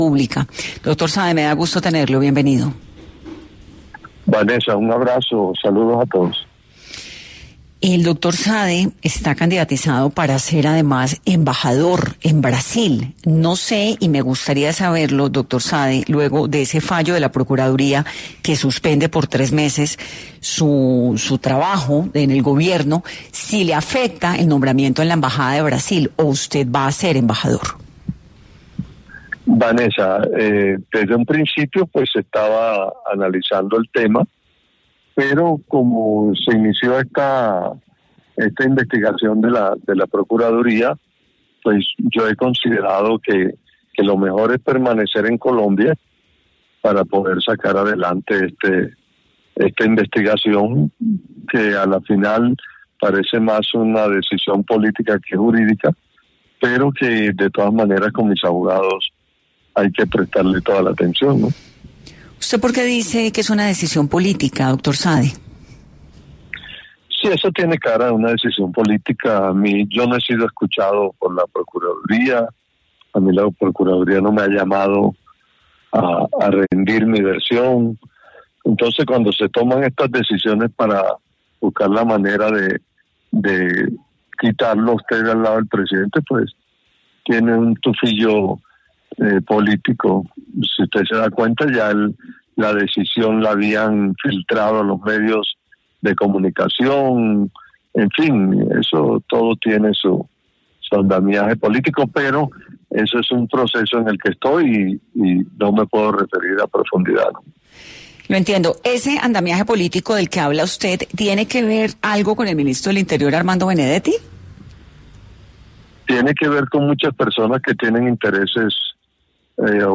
En una entrevista concedida a 10AM de Caracol Radio, Saade afirmó que la decisión de la Procuraduría tiene “un tufillo político” y que no fue escuchado antes de ser sancionado.